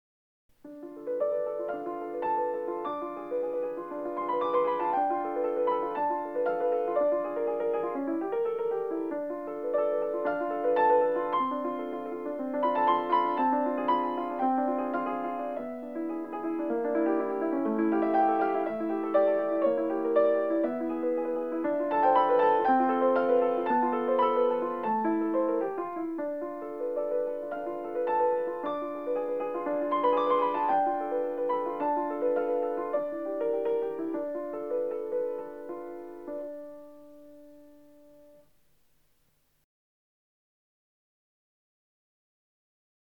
Arroyuelo para piano